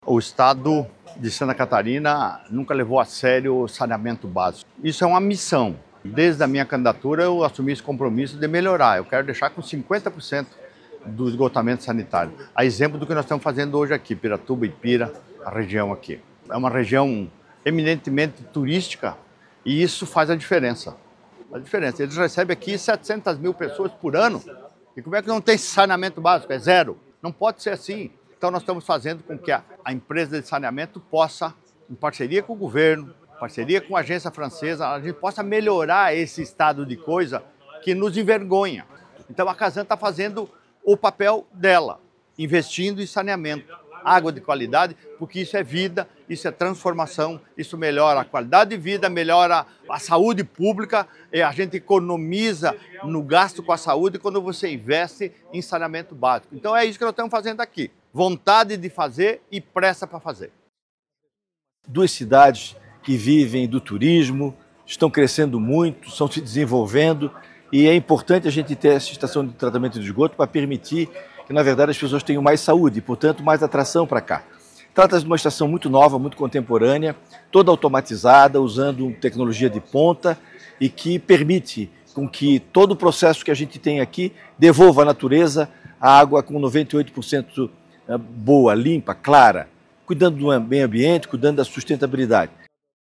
O evento de inauguração aconteceu neste sábado (17), na Estação de Tratamento de Esgoto (ETE) em Piratuba, com a presença do governador Jorginho Mello e do presidente da CASAN (Companhia Catarinense de Águas e Saneamento), Edson Moritz.
Para o governador, cuidar do saneamento básico do estado é uma missão e um compromisso de campanha:
O presidente da Casan falou da tecnologia utilizada para cuidar da sustentabilidade de duas cidades turísticas do estado:
SECOM-Sonoras-Inauguracao-esgotamento-sanitario-de-Ipira-Piratuba.mp3